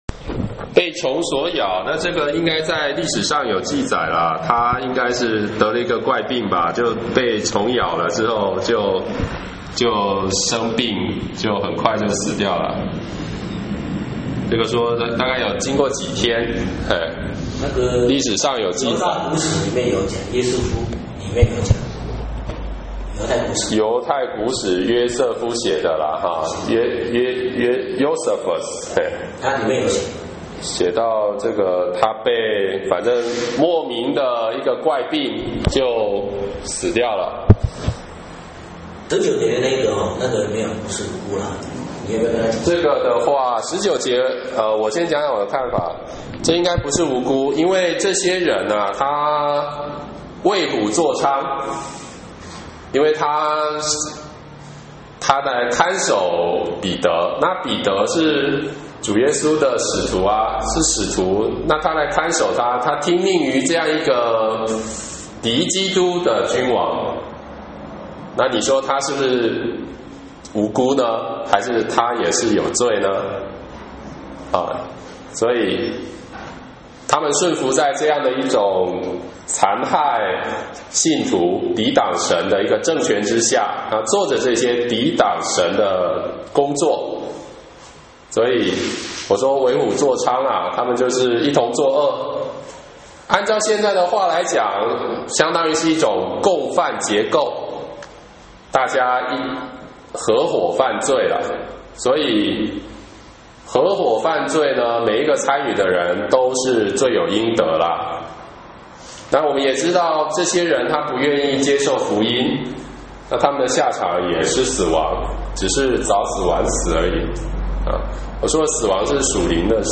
（使徒行傳 12:1-25）講解-1